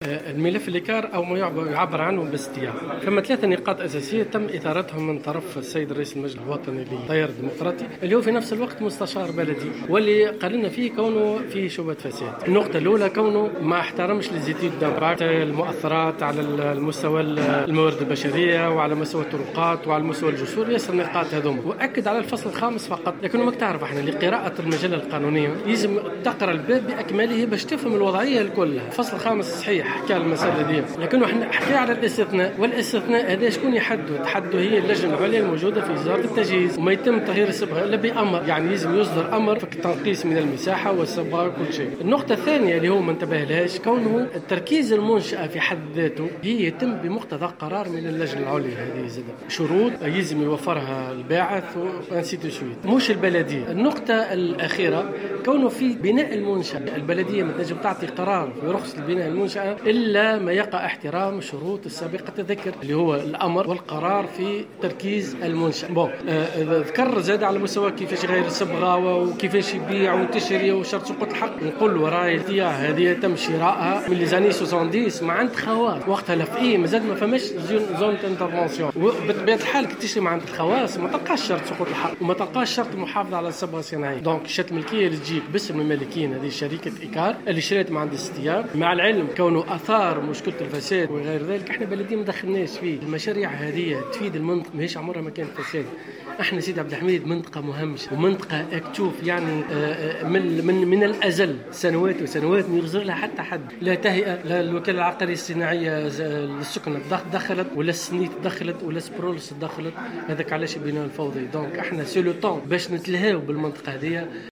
رد رئيس الدائرة البلدية سوسة سيدي عبد الحميد صابر دريرة في تصريح للجوهرة اف ام...